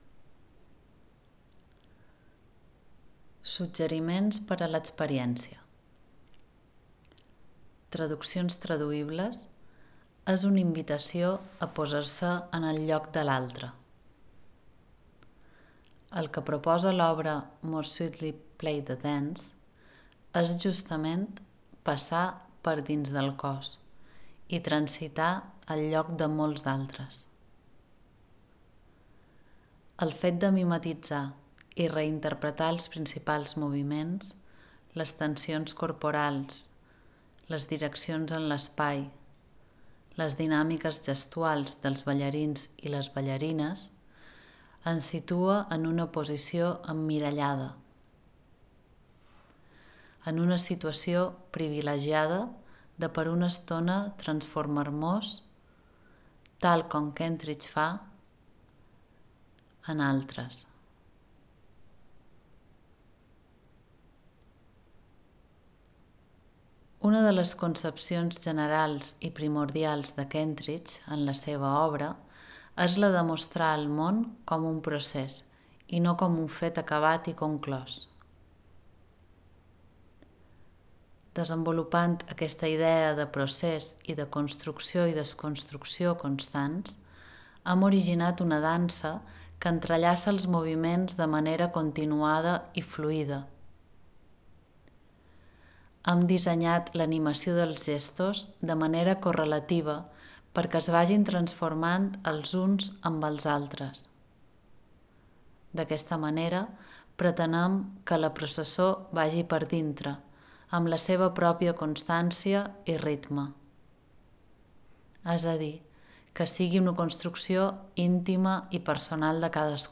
Aquesta activitat és en format àudio, gratuït i d’ús autònom i té el propòsit que el visitant pugui gaudir la instal·lació More Sweetly Play de Dance amb el cos, des de casa i sense veure la peça amb els ulls. Dirigit especialment a persones amb diversitat visual i a tothom que tingui ganes de tenir una experiència amb aquesta obra a través del cos.